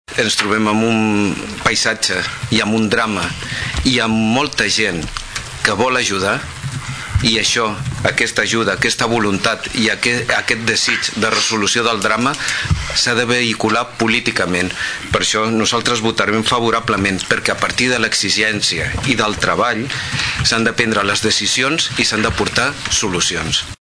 El regidor del PSC, Rafa Delgado parlava de decisions inhumanes i polítiques errònies que afecten a milers de persones.